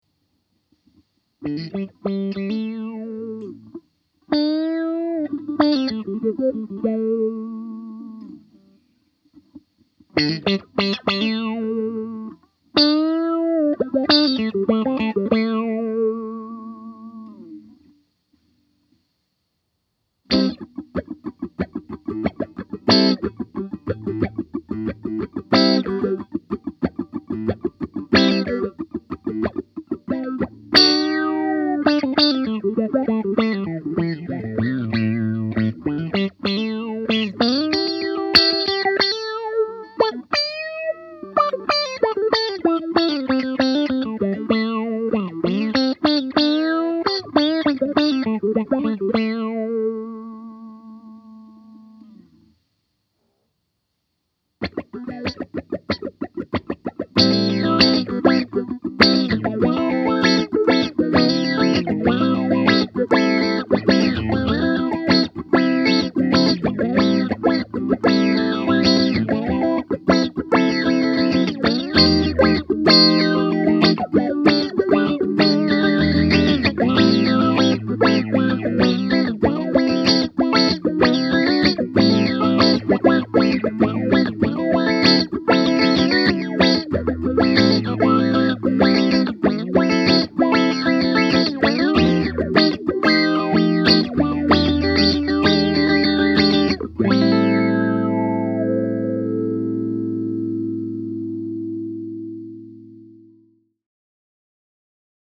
The following clips were all played with my Fender American Deluxe Strat into my trusty Roland Cube 60, set to a clean “Blackface” setting.
Next, it’s just my guitar and the pedal. The first part of the clip demonstrates the pedal’s response to pick attack. Then I just play a random lead and some fast funk chords.